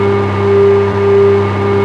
rr3-assets/files/.depot/audio/sfx/electric/mp4x_off_high_12000rpm.wav